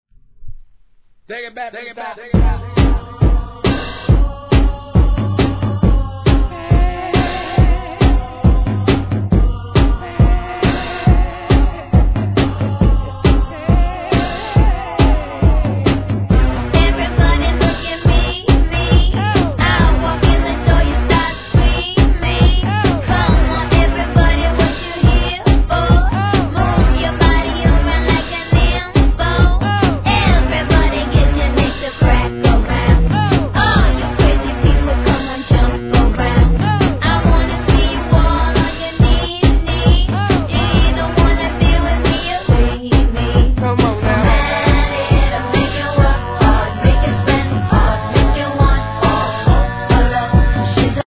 HIP HOP/R&B
アップテンポのビートと印象的なサンプリングで耳に残ります。